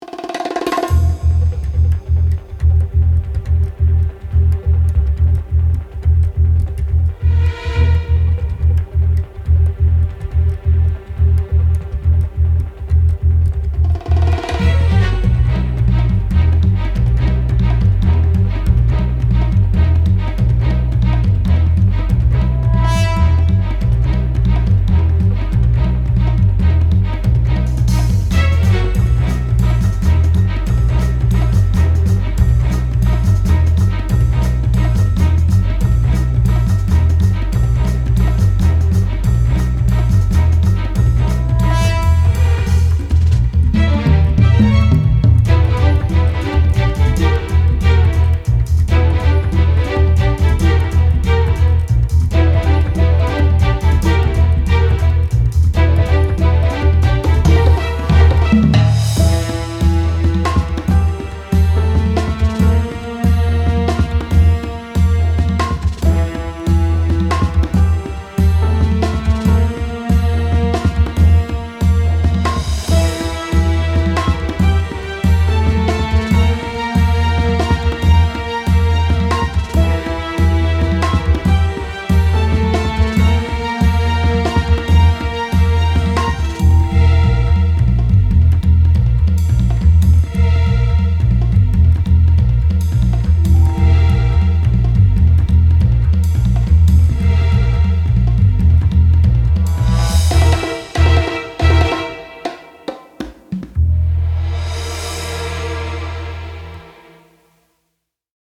スパイを彷彿とさせるクールなBGM
アコースティック, シネマチック 1:48 ダウンロード